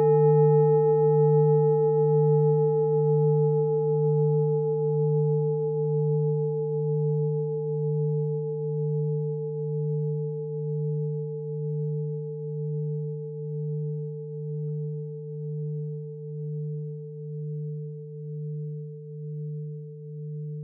Klangschale Bengalen Nr.17
Klangschale-Durchmesser: 23,9cm
Sie ist neu und wurde gezielt nach altem 7-Metalle-Rezept in Handarbeit gezogen und gehämmert.
(Ermittelt mit dem Filzklöppel)
In unserer Tonleiter befindet sich diese Frequenz nahe beim "Dis".